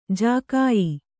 ♪ jākāyi